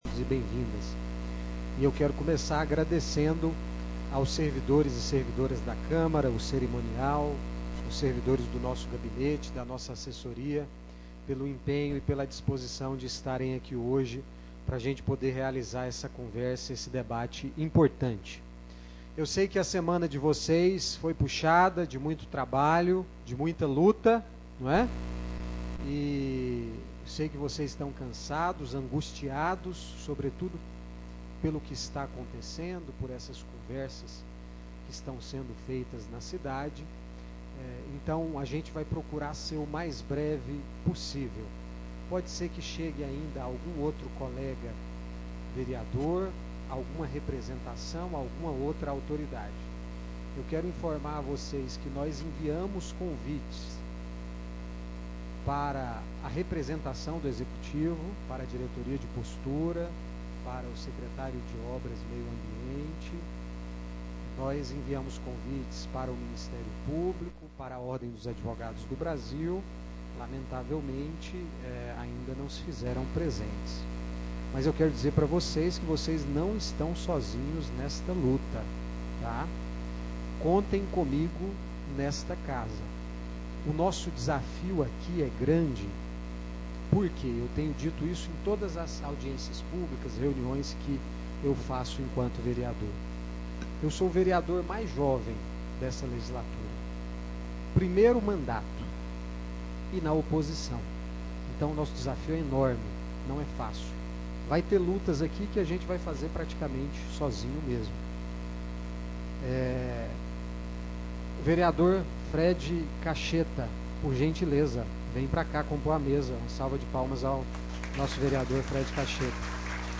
Audiência Pública Trabalhadores Ambulantes de Anápolis. Dia 21/03/2025.